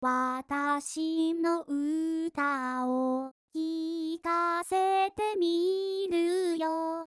個性的な歌い方にする
これでテンポが128BPMに設定されました。